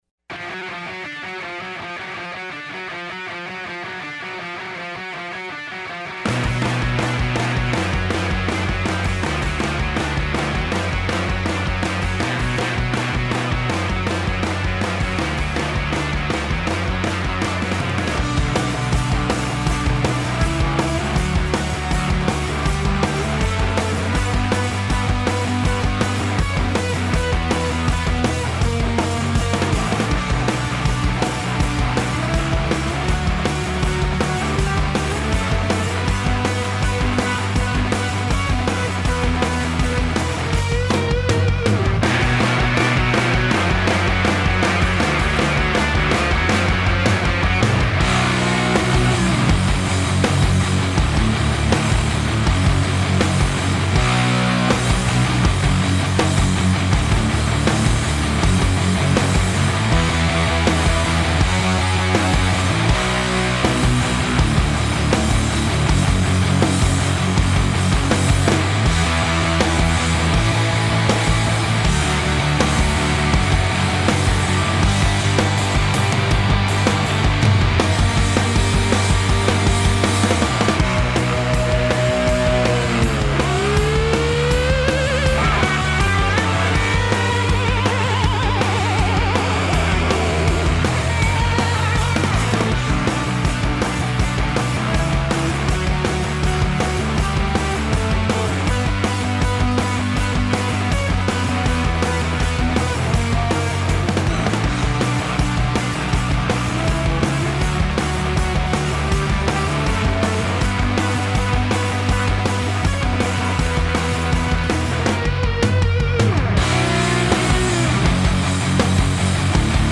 11 TSP (Instrumental).mp3